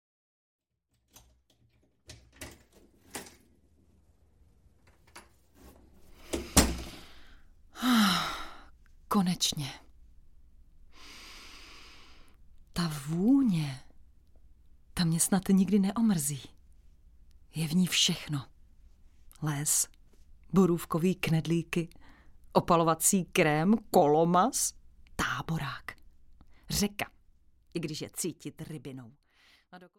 Pociť pocit audiokniha
Ukázka z knihy